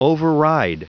Prononciation du mot override en anglais (fichier audio)
Prononciation du mot : override